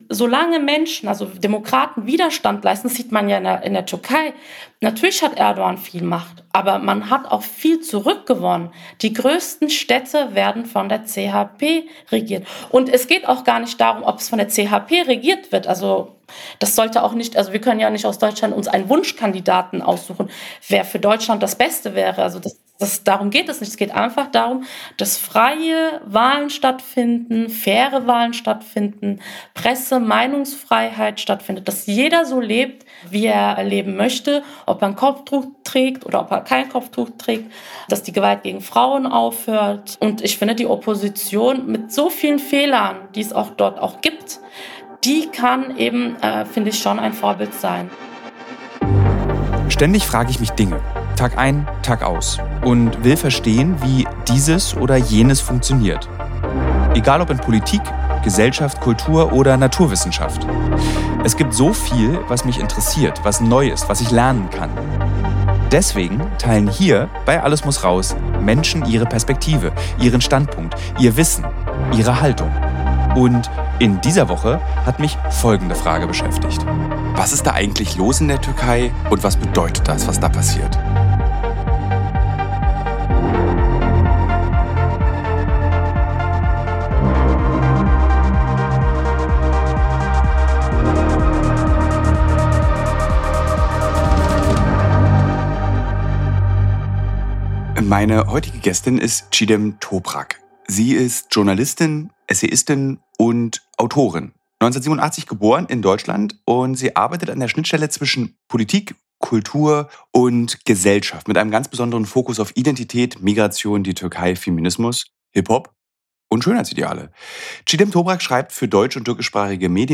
Das beide heute dort sein können und offen sprechen können, liegt daran, dass Rebellen unter der Führung der Gruppe Hajat Tahrir al-Scham (HTS) vor ungefähr einer Woche Damask…